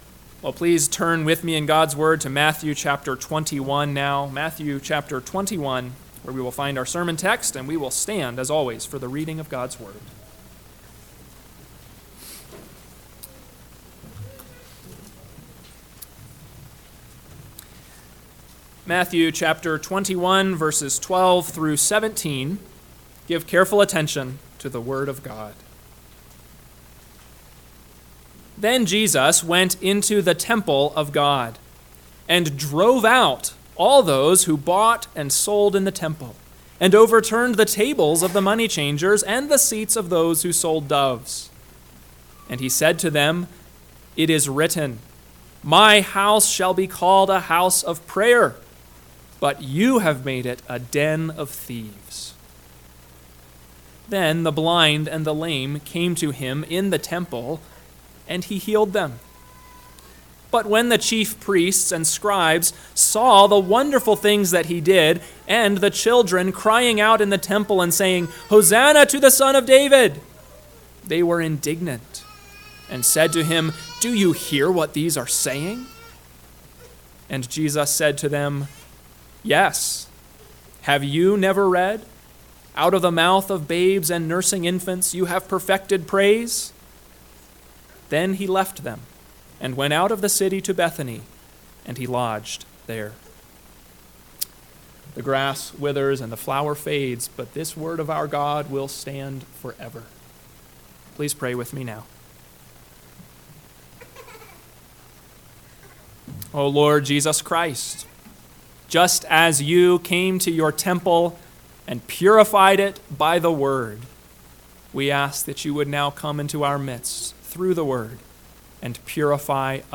AM Sermon – 8/25/2024 – Matthew 21:12-17 – Northwoods Sermons